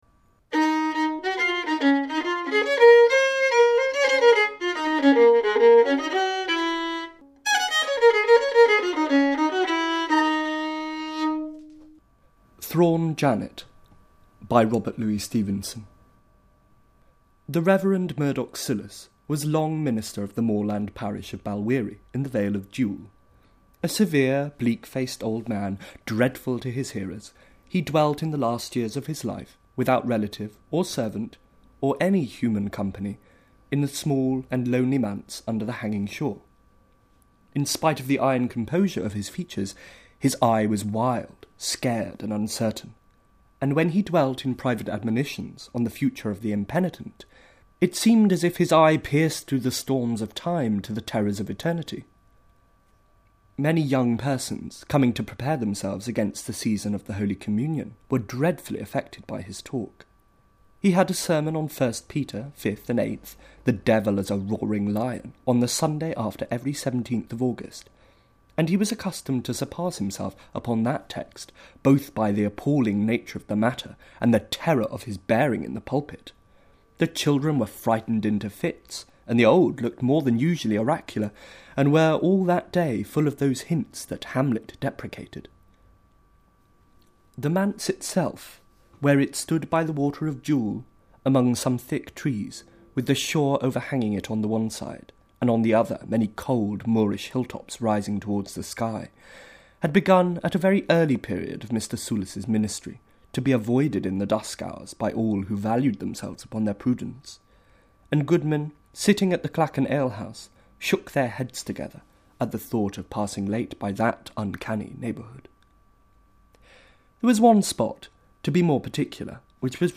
This voice, which delivers the Gothic content and is the second of two we encounter in the story, estranges at the same time as entrances the implied reader, who has already been interpellated by the initial non-dialect, RP voice, as one of his kind: that is, civilized, educated, the kind of person that reads the Cornhill.
The latter voice migrates from the realm of silent reading difficulty to that of sonic pleasure, the meaning-making musicality of its lively, emotionally engaged speech comes through strong in the recording, commanding an immediate authority that, I think, is more slowly won, when the story is read silently – at least, by those of us for whom the dia